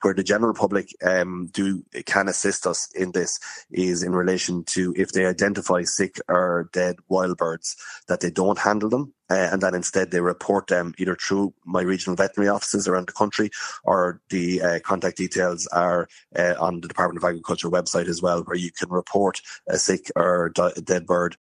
Minister Martin Heydon is appealing to people to take extreme care……………..